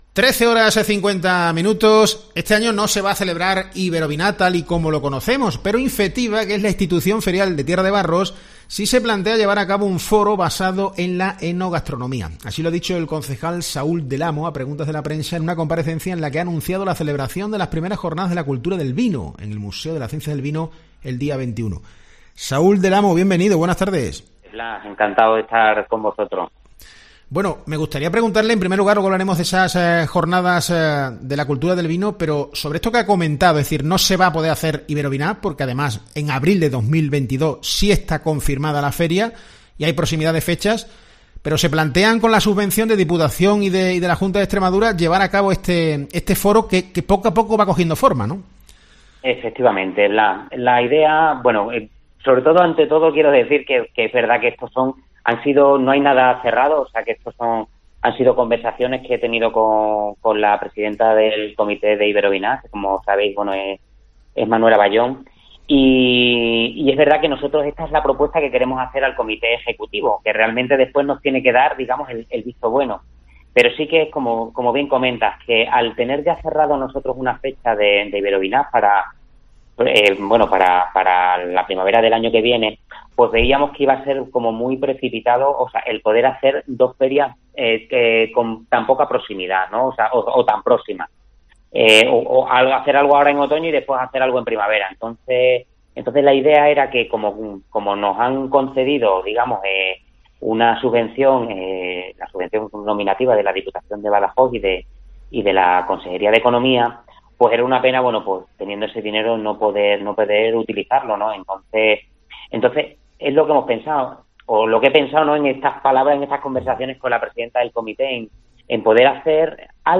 El concejal de la Institución Ferial de Tierra de Barros, Saúl del Amo, ha pasado por COPE Almendralejo para hablar de las primeras jorrnadas de la cultura del vino, a celebrar el día 21 en el Museo de las Ciencias del Vino, y los planes del gobierno local sobre el Salón del Vino y la Aceituna, que se celebró la última vez en formato virtual por la pandemia. Del Amo ha anunciado que Iberovinac se hará en abril de 2022 en el Palacio del Vino y la Aceituna.